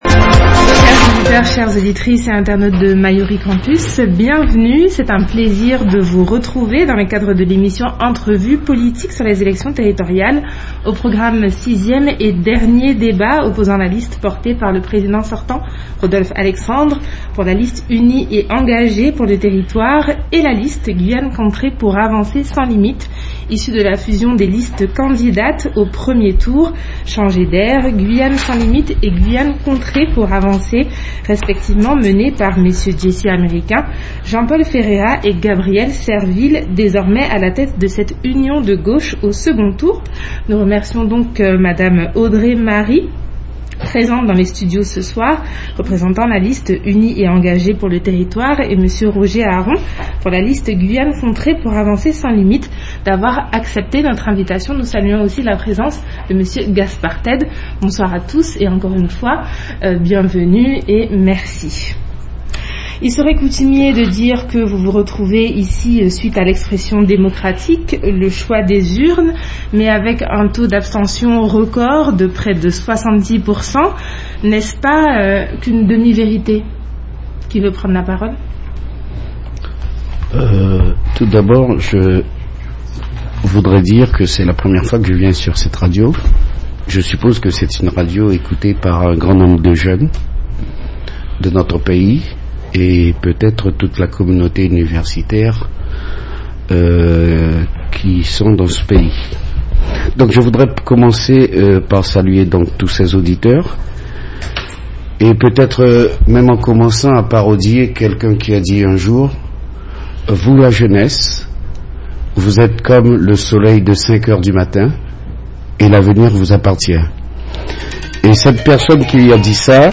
Grand débat en vue du second tour des élections territoriales à la CTG en Guyane
Entrevue politique, spéciales élections territoriales, en vue du second tour en Guyane.